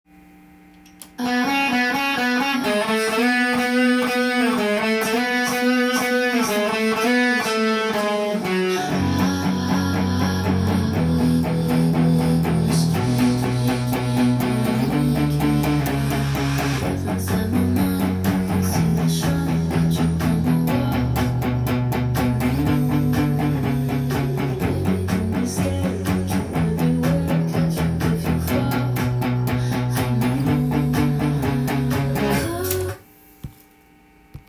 音源にあわせて譜面通り弾いてみました
けだるい感じがするダークな曲
シンプルなコード進行でkeyがF♯ｍになっています。
イントロのみメロディーラインとユニゾンという
エレキギターでカンタンに弾けてしまいます。